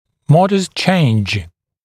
[‘mɔdɪst ʧeɪnʤ][‘модист чэйндж]умеренное изменение, незначительное изменение